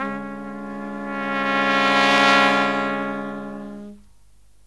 LONG HIT09-R.wav